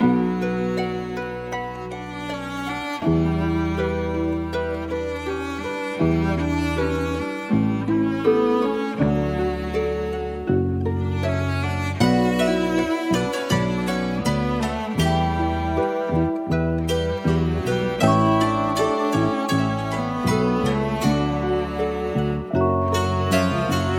Each sample is meticulously crafted to help you achieve the same hard-hitting and soulful vibe that Divine is known for.
Gully-Loops-Dharavi-Melody-Loop-BPM-80-D-Maj.wav